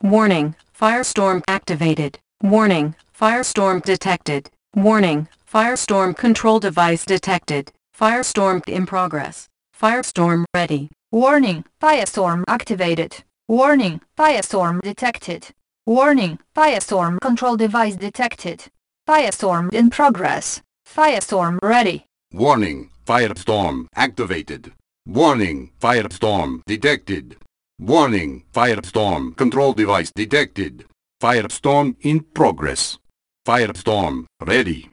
Subject description: EVA sounds for all 3 sides   Reply with quote  Mark this post and the followings unread
Pretty much yeah, a little editing, but otherwise just cutting and pasting audio from other EVAs.
The tone and diction fits well!